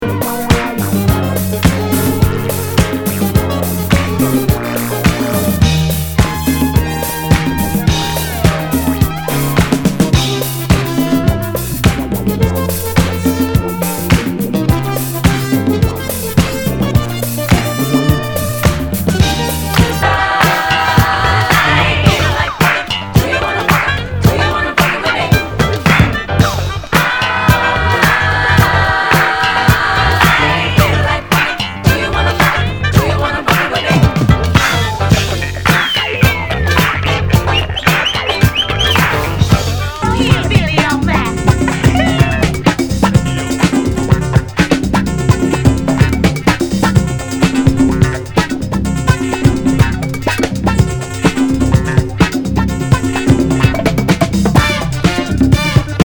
類別 R&B、靈魂樂
SOUL/FUNK/DISCO
ナイス！ファンク！